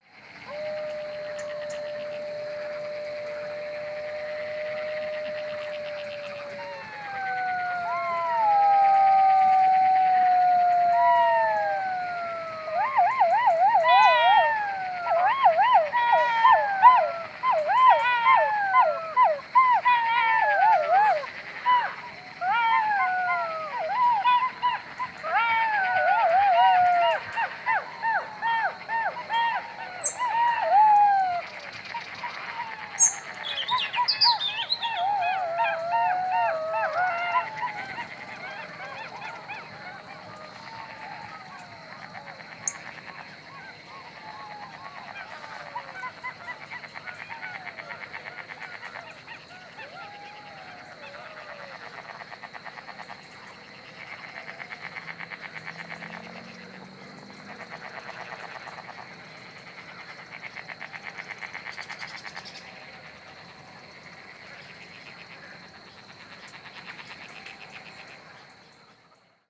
9. American Three-toed Woodpecker (Picoides dorsalis) (Rare/Occasional)
Call: Soft, nasal “kik” and tapping.